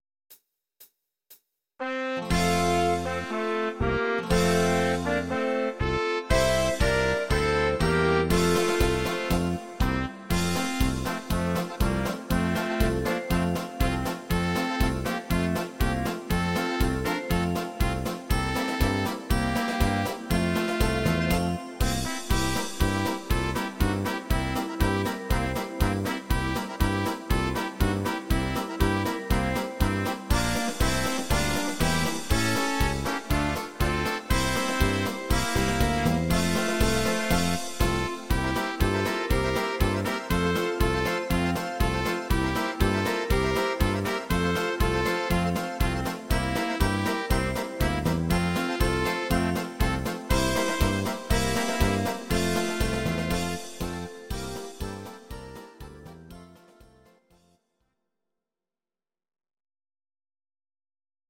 These are MP3 versions of our MIDI file catalogue.
Please note: no vocals and no karaoke included.
Volkslied